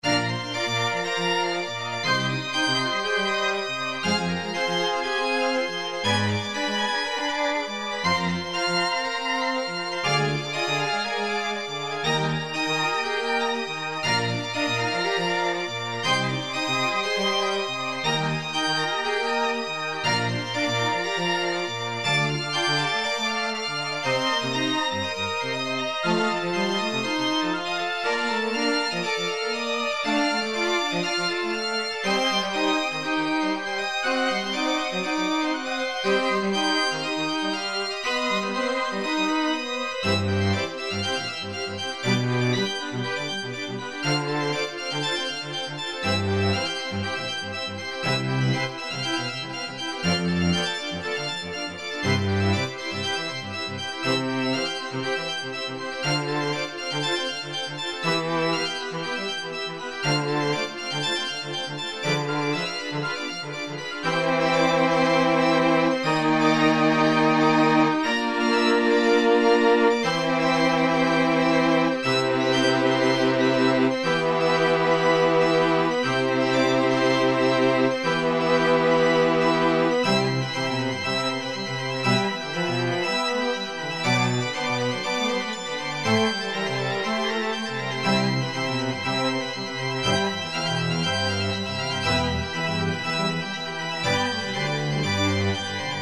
チャーチオルガン(パイプ)、バイオリン、ビオラ、チェロ